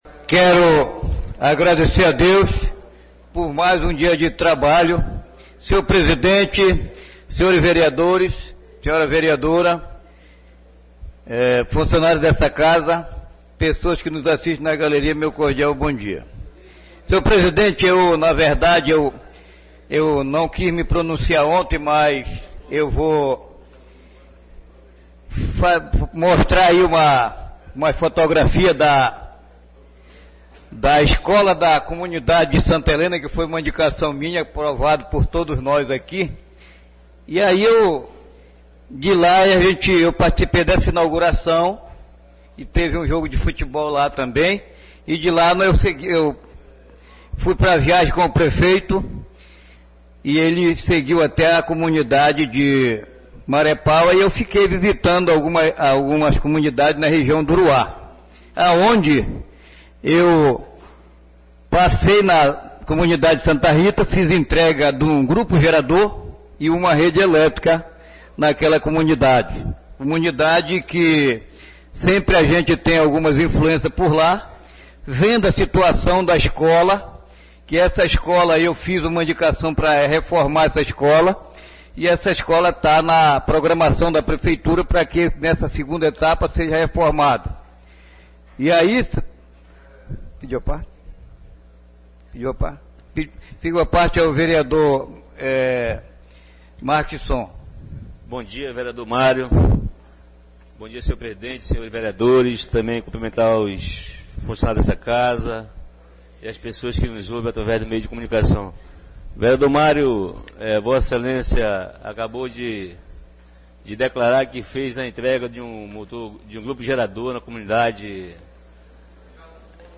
Oradores do Expediente (37ª Sessão Ordinária da 3ª Sessão Legislativa da 31ª Legislatura)